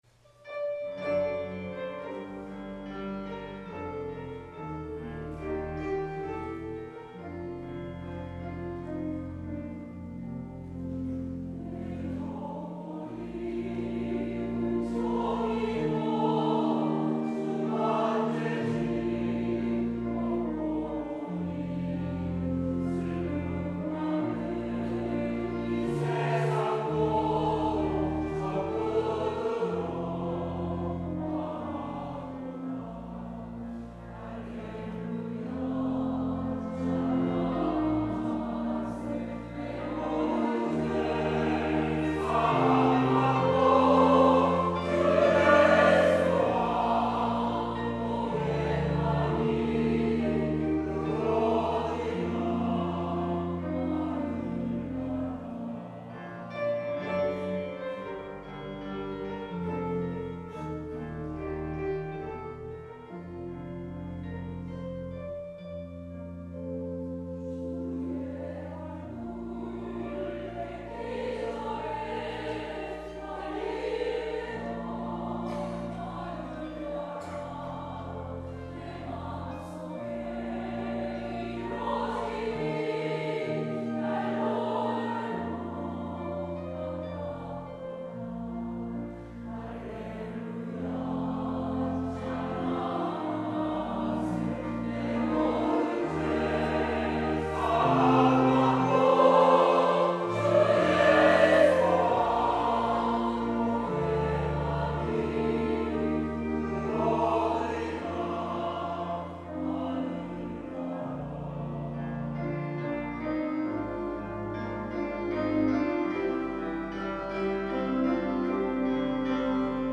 성가대 찬양